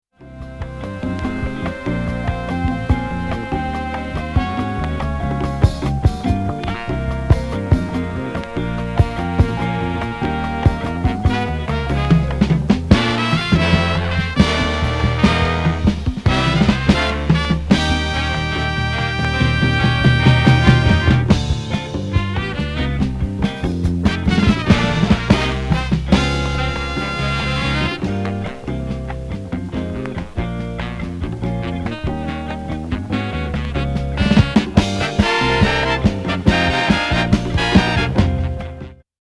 グルーヴィー・ジャジー・ラテン・インスト